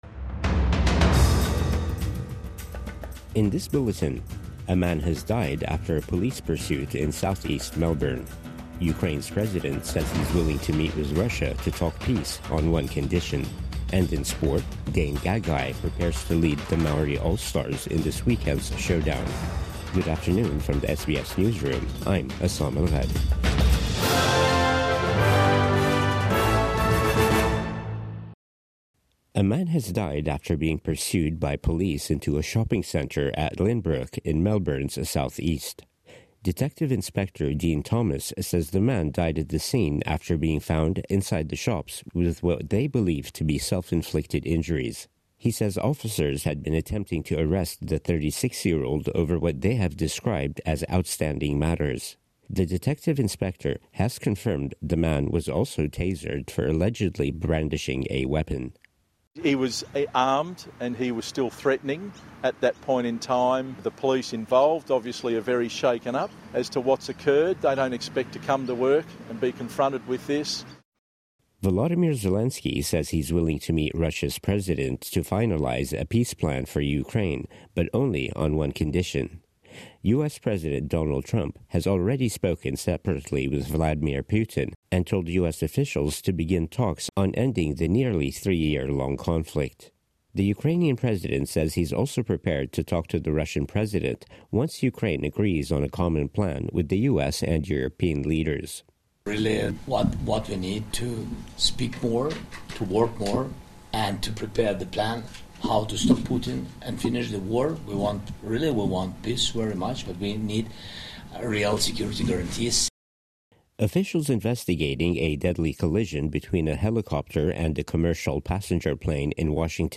Midday News Bulletin 15 February 2025